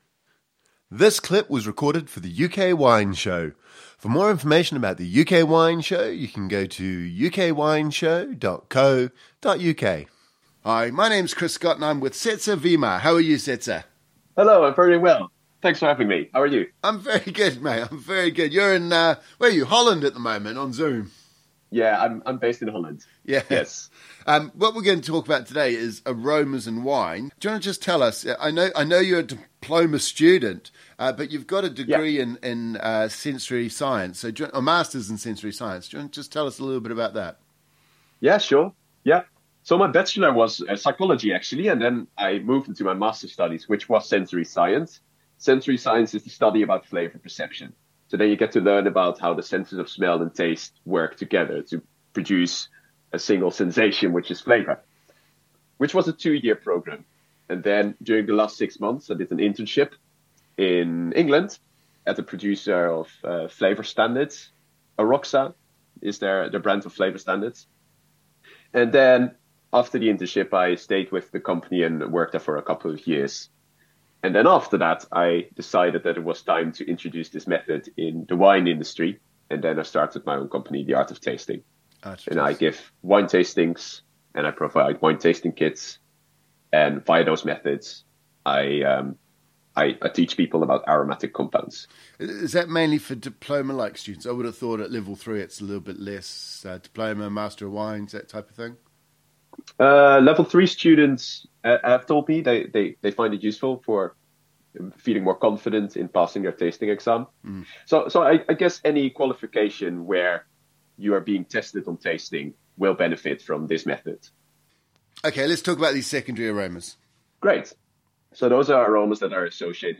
In this second interview, we look at secondary and tertiary aromas in wine.